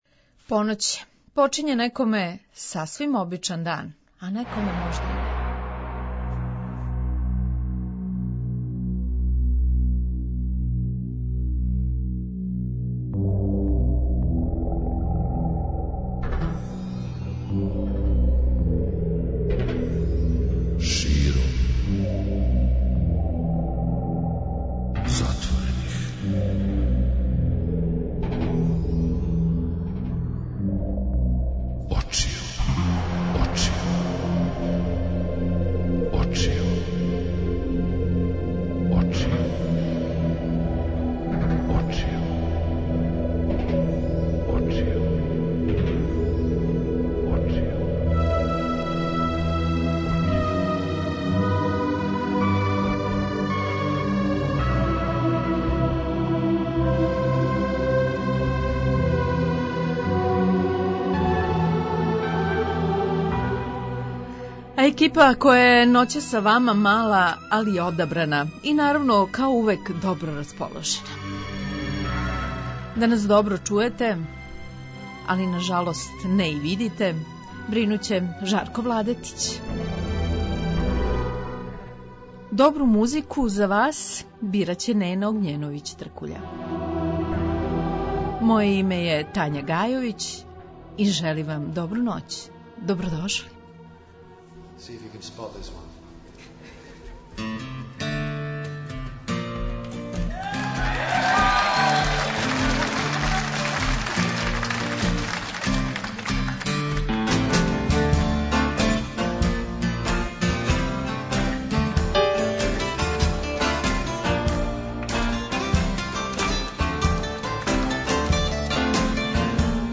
Такође, отпаковаћемо поклоне и прославићемо уживо и један рођендан, а чији - на вама је да откријете.
преузми : 57.09 MB Широм затворених очију Autor: Београд 202 Ноћни програм Београда 202 [ детаљније ] Све епизоде серијала Београд 202 Говор и музика Састанак наше радијске заједнице We care about disco!!!